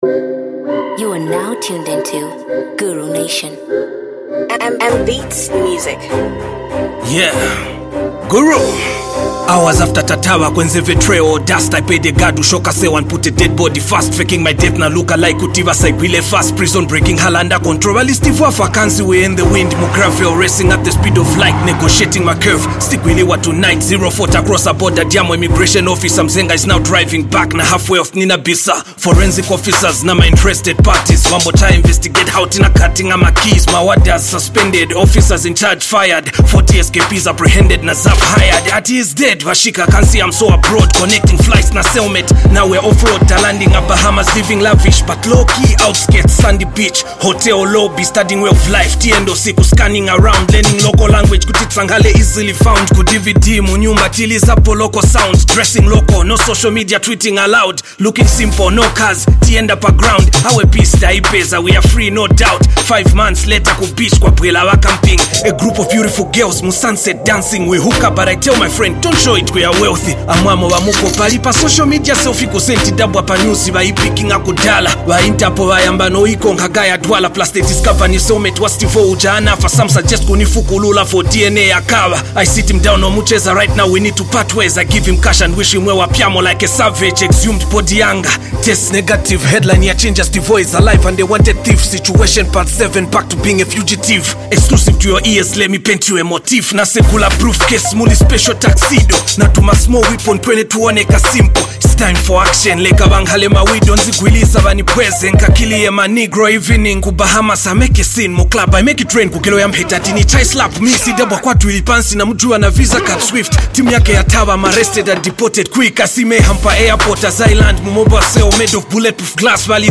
Zambian rapper